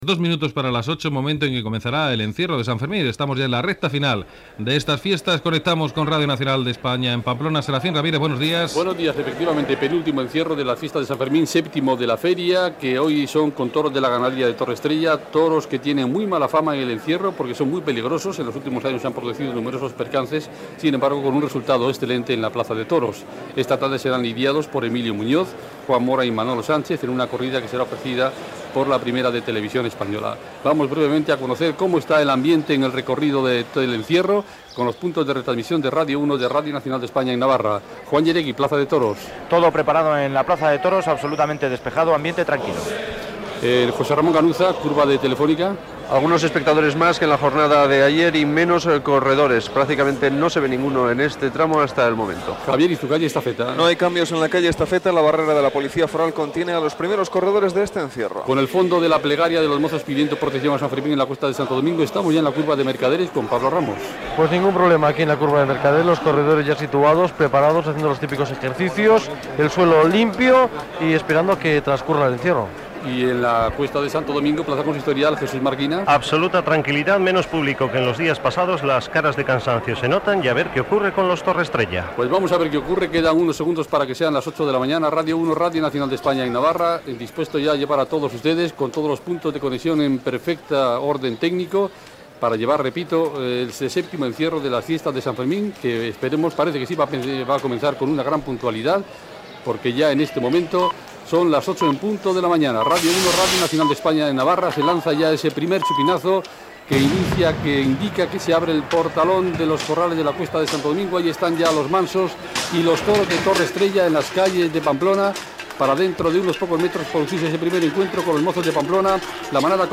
Hora, transmissió des de Pamplona del "encierro" dels braus de Torrestrella
Informatiu